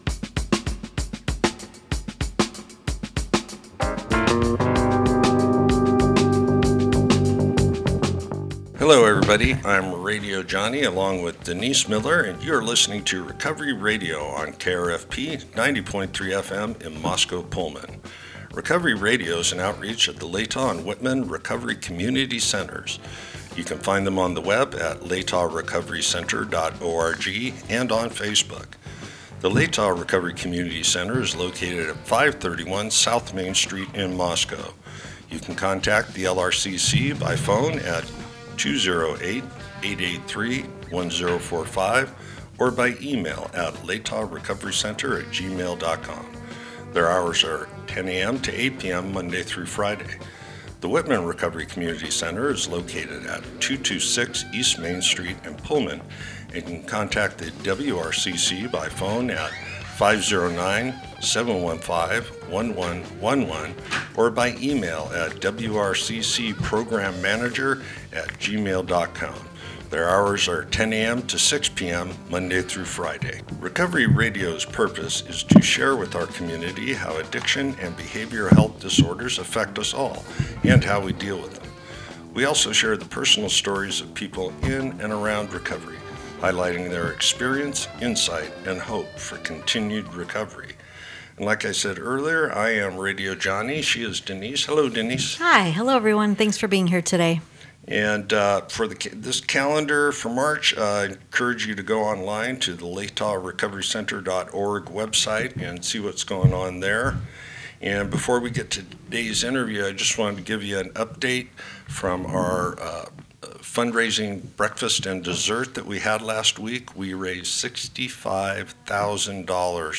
Type: Interview